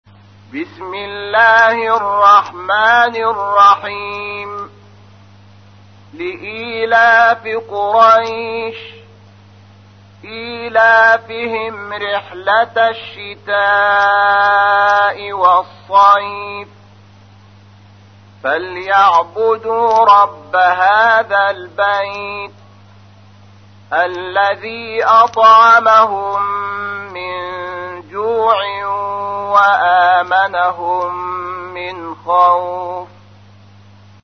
تحميل : 106. سورة قريش / القارئ شحات محمد انور / القرآن الكريم / موقع يا حسين